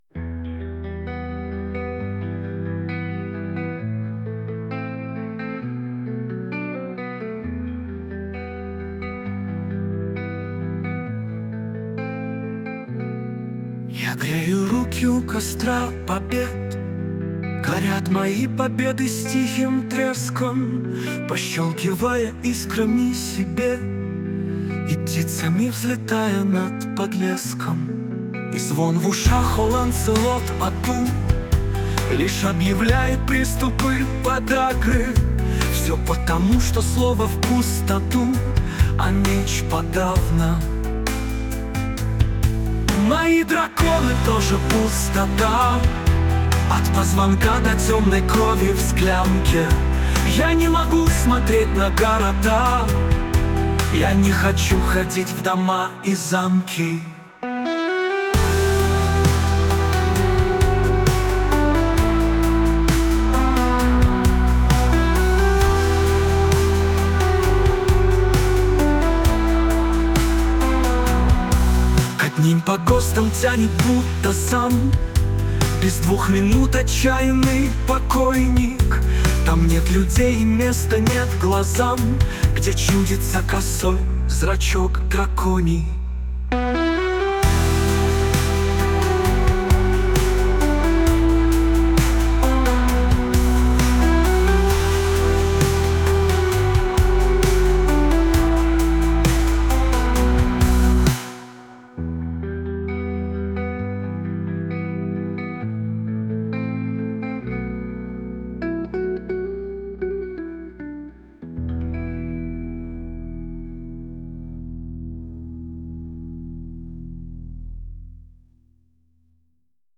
Стихи, понятно, древние мои, музыка и голос, понятно, нейросетевые.
Нейросеть где-то понимает "ё" в простой "е", а где-то нет.